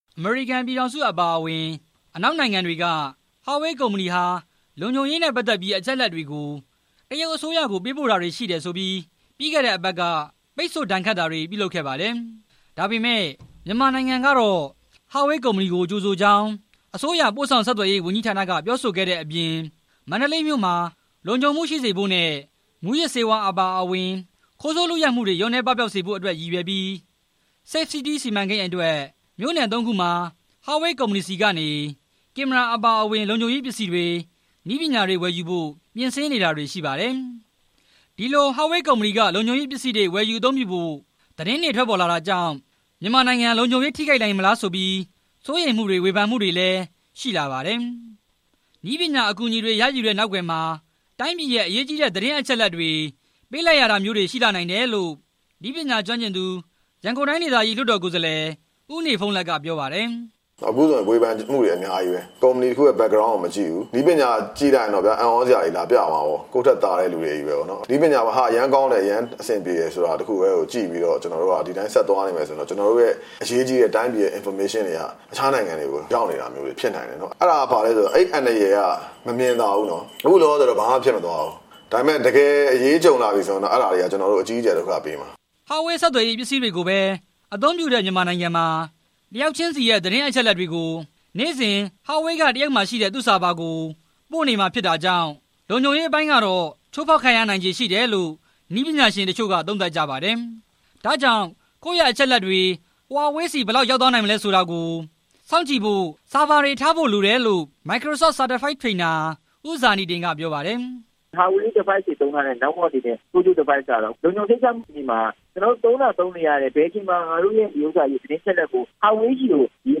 နည်းပညာအကူညီတွေရယူတဲ့နောက်ကွယ်မှာ တိုင်းပြည်ရဲ့အရေးကြီးတဲ့ သတင်းအချက်တွေ ပေးလိုက်ရတာမျိုးတွေရှိနိုင်တယ်လို့ နည်းပညာကျွမ်းကျင်သူ ရန်ကုန်တိုင်းဒေသကြီး လွှတ်တော်ကိုယ်စားလှယ် ဦးနေဘုန်းလတ်က ပြောပါတယ်။